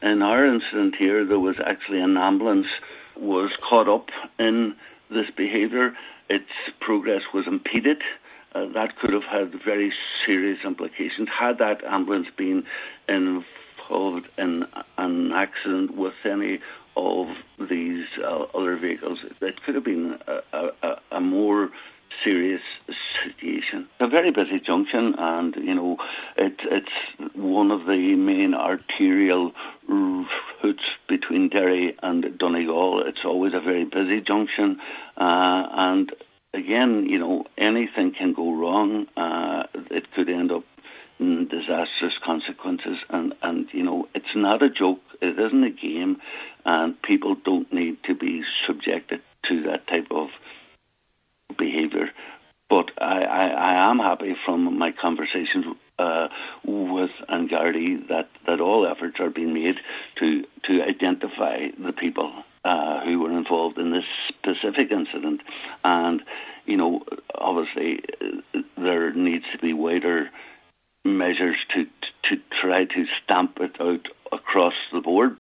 Councillor Terry Crossan says the situation could have been a lot more serious: